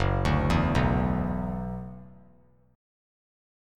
F#7sus4#5 Chord
Listen to F#7sus4#5 strummed